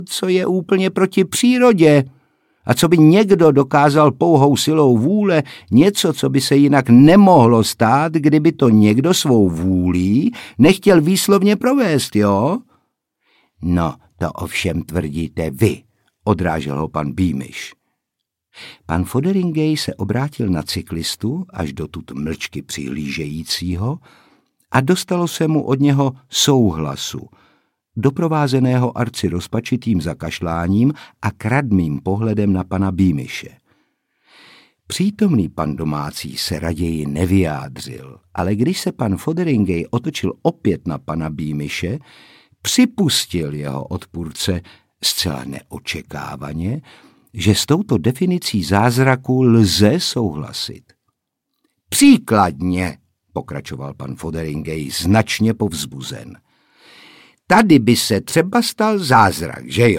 Audiobook
Read: Otakar Brousek Jr.